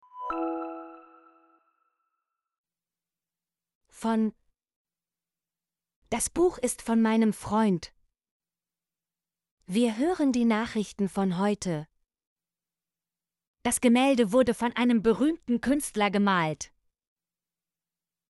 von - Example Sentences & Pronunciation, German Frequency List